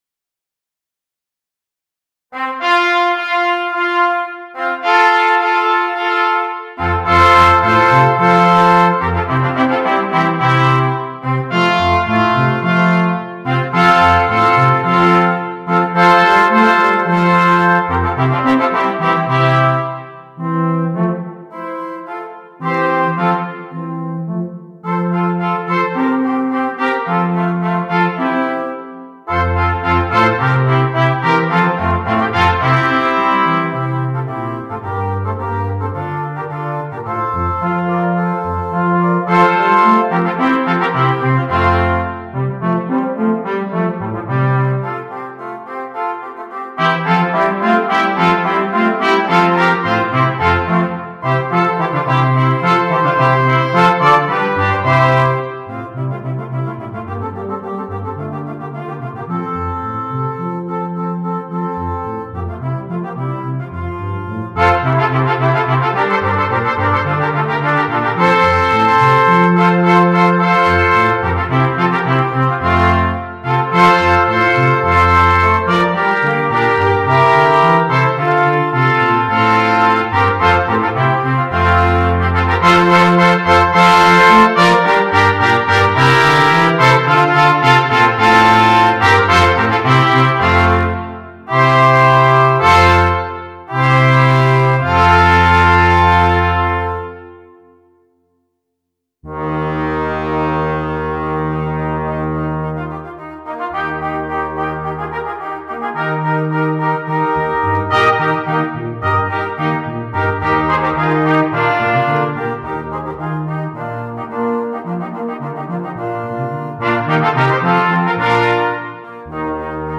Gattung: für Blechbläserquartett
Besetzung: Ensemblemusik für 4 Blechbläser
2 Cornets, Eb Horn & Euphonium
2 Trumpets, 2 Trombones